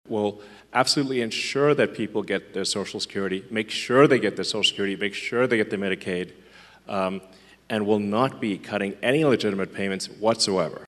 DOGE CHAIRMAN ELON MUSK HOSTED A TOWN HALL IN GREEN BAY, WISCONSIN SUNDAY TO SHORE UP SUPPORT FOR WISCONSIN SUPREME COURT CANDIDATE BRAD SCHIMEL AHEAD OF THE STATE’S ELECTION ON TUESDAY. WITH AMERICANS CONCERNED ABOUT PROPOSED CUTS TO SOCIAL SECURITY, MEDICAID, AND MEDICARE … MUSK REASSURED VOTERS THAT THEIR BENEFITS WON’T BE CUT…